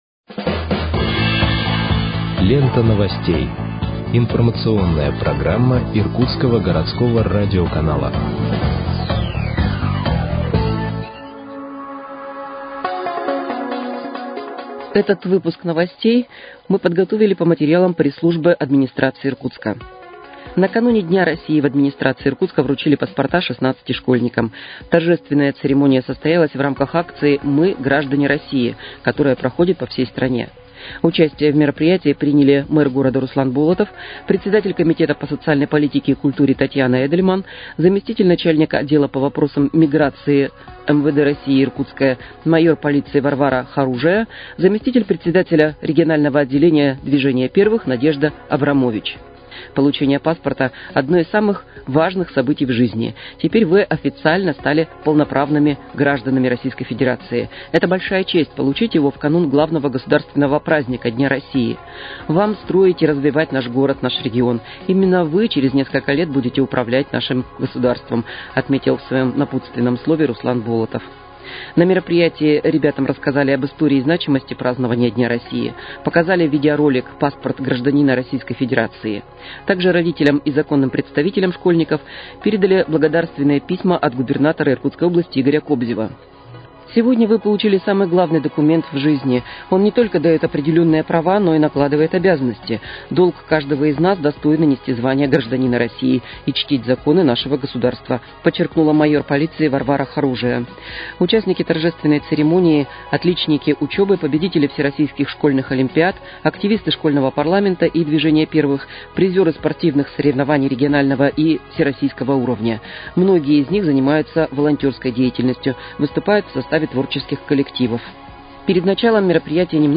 Выпуск новостей в подкастах газеты «Иркутск» от 18.06.2025 № 2